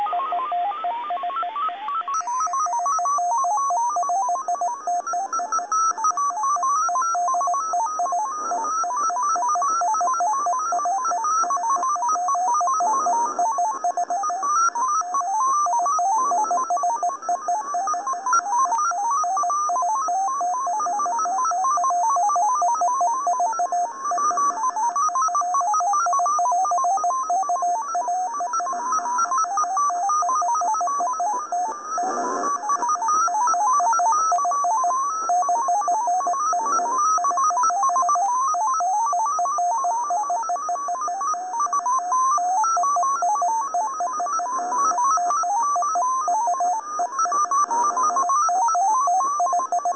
MFSK-45